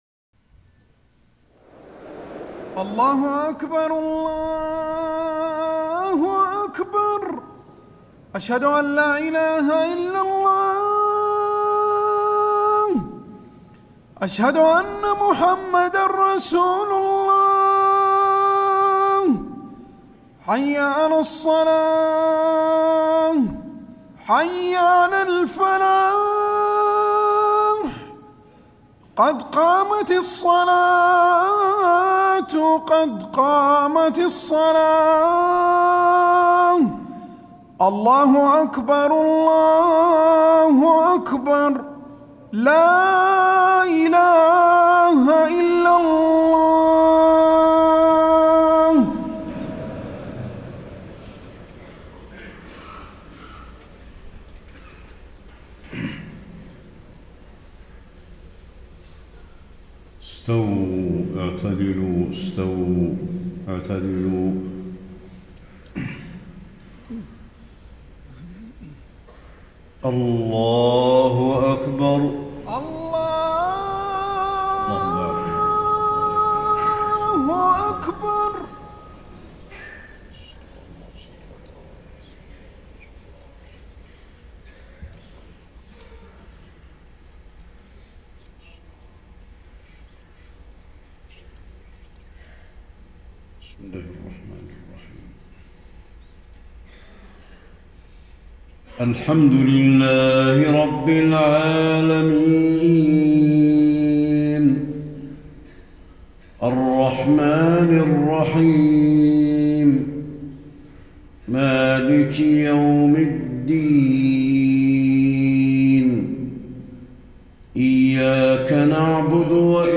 فجر 14 شعبان ١٤٣٥ من سورة الدخان > 1435 🕌 > الفروض - تلاوات الحرمين